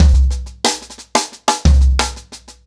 Wireless-90BPM.47.wav